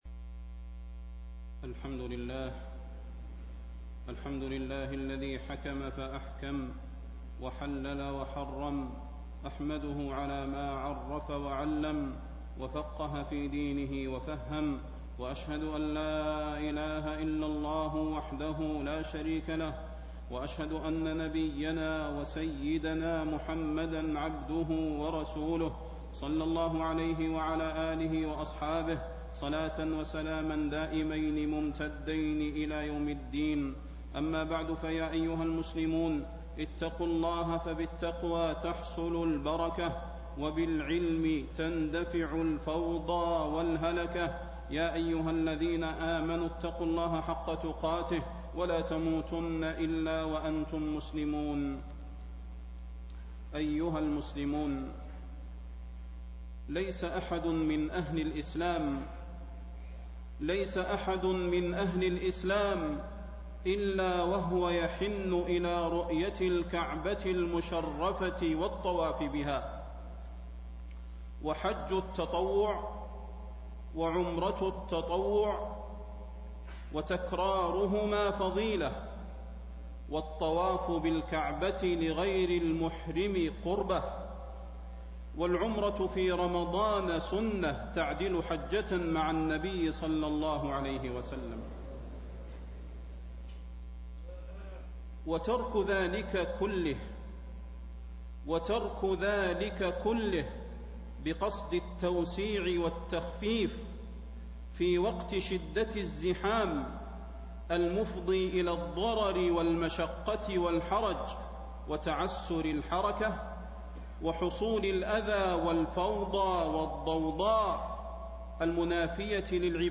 تاريخ النشر ٢٦ شعبان ١٤٣٤ هـ المكان: المسجد النبوي الشيخ: فضيلة الشيخ د. صلاح بن محمد البدير فضيلة الشيخ د. صلاح بن محمد البدير رمضان والتيسير في العبادة